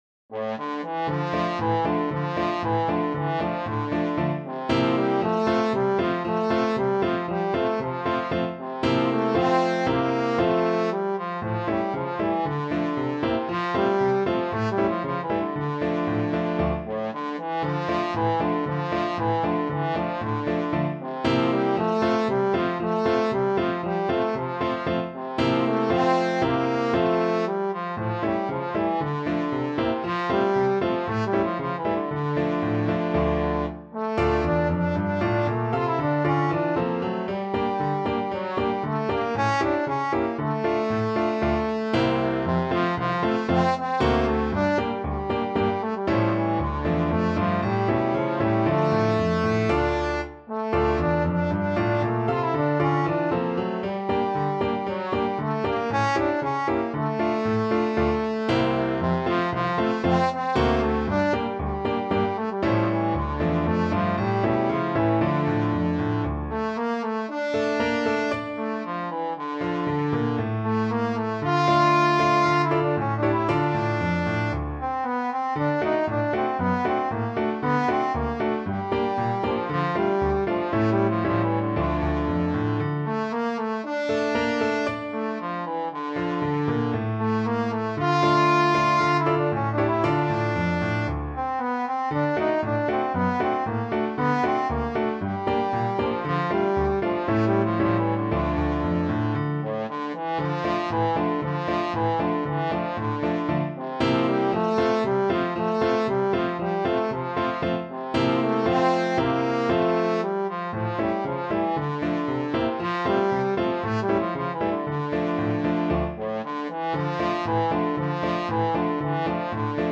2/4 (View more 2/4 Music)
Allegro =c.116 (View more music marked Allegro)
Traditional (View more Traditional Trombone Music)